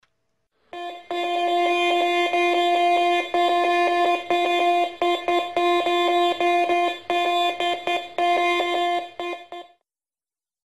Звуки металлоискателя
На этой странице собраны звуки металлоискателей разных типов: от монотонных сигналов старых моделей до современных многозональных тонов.